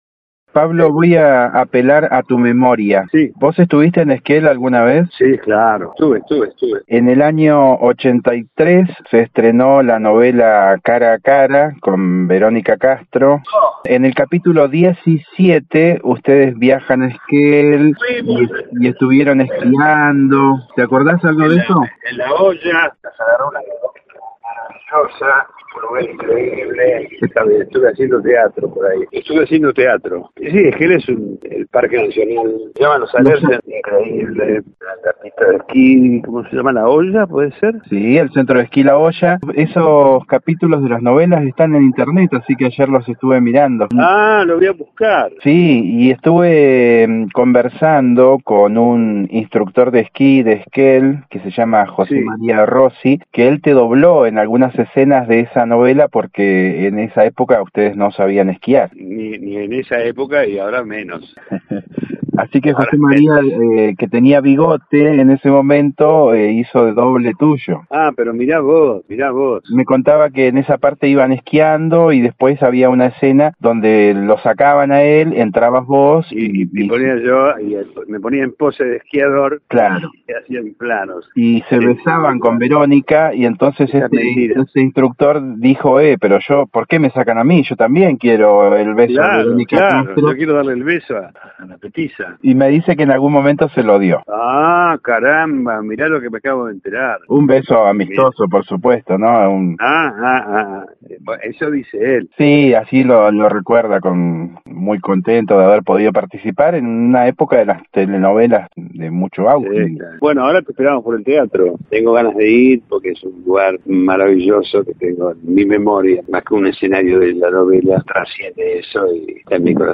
A raíz de esta nueva visita de Alarcón a Esquel, Noticias de Esquel conversó con el reconocido actor de cine, teatro y televisión, recordando un viaje que hizo a Esquel como protagonista de la telenovela Cara a Cara, donde formaba pareja con Verónica Castro y parte de esta novela se rodó en nuestra ciudad, donde dos esquelenses aparecen en una escena de la novela, doblando a Verónica Castro y Pablo Alarcón ya que estas estrellas del espectáculo no sabían esquiar.